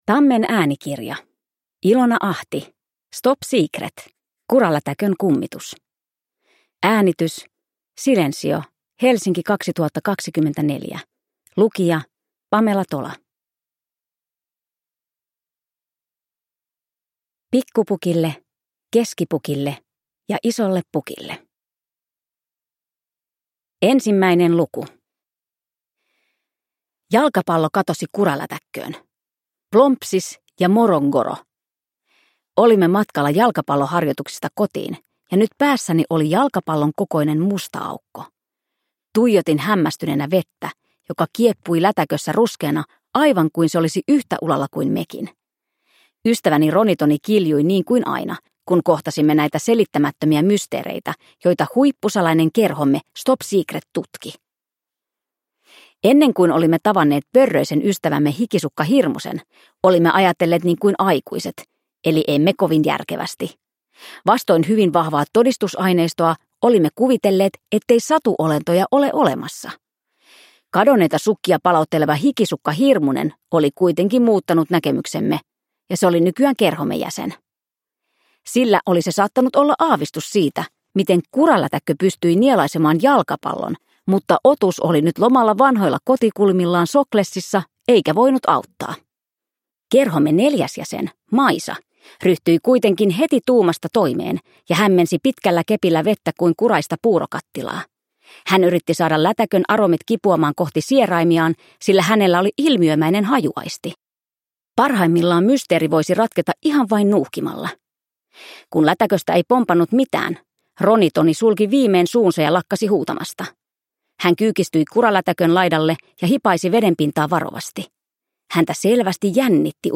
Stop Secret. Kuralätäkön kummitus – Ljudbok
Uppläsare: Pamela Tola